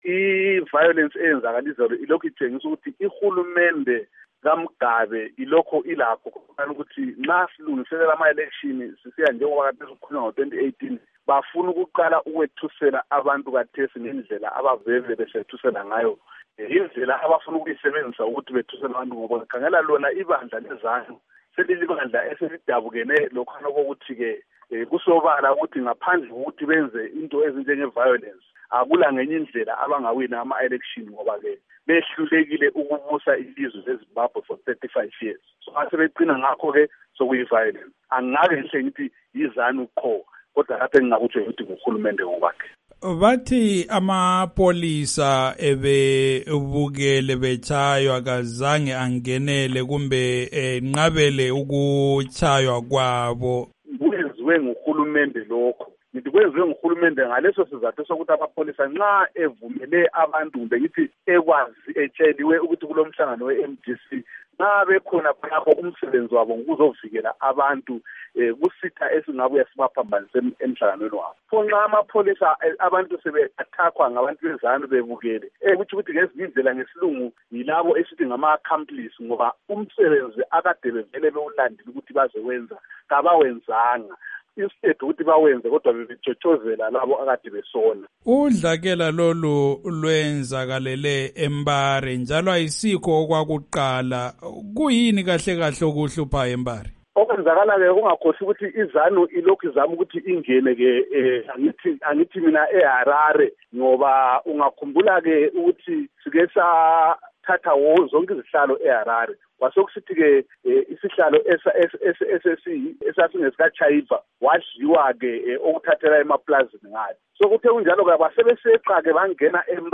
Ingxoxo loMnu Abednico Bhebhe